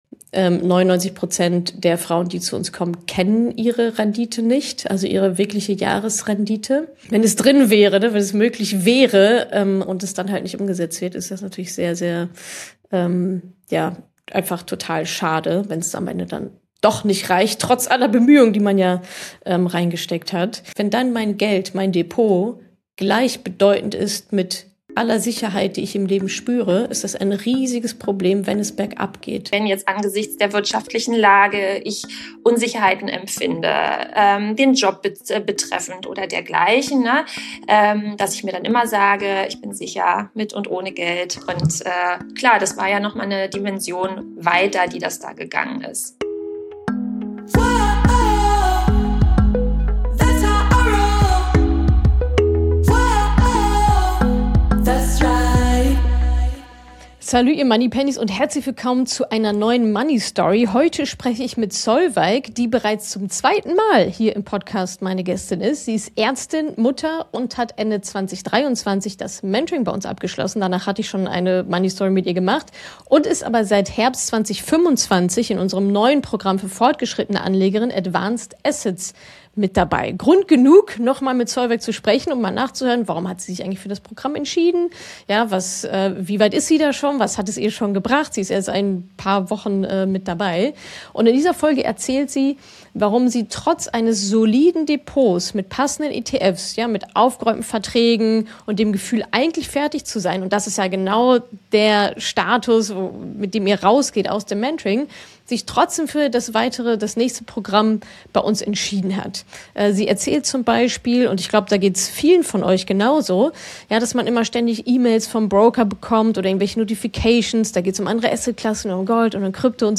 Wir sprechen in diesem Interview über ihren Einstieg in die Diskussion über toxische Weiblichkeit, die wir dringend führen müssen.